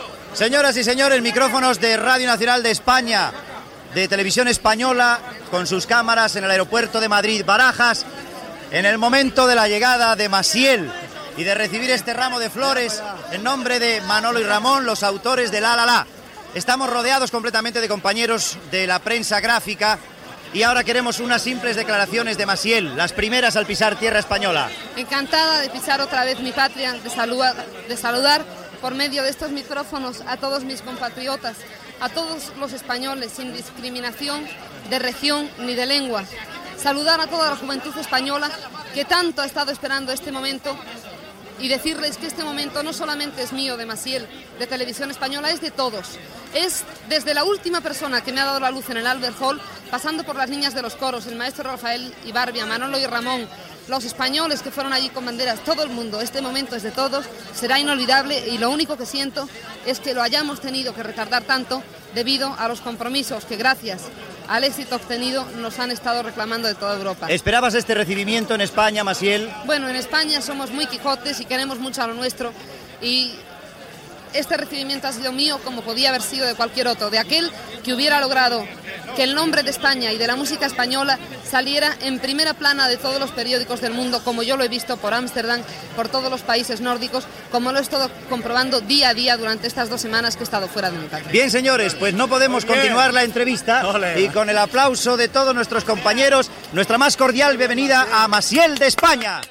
Arribada de la cantant Massiel (María de los Ángeles Félix Santamaría Espinosa) a l'aeroport de Madrid després de guanyar el Festival d'Eurovisió el dia 8 d'abril
Informatiu